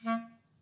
Clarinet
DCClar_stac_A#2_v2_rr2_sum.wav